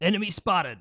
Voices / Male
Enemy Spotted.wav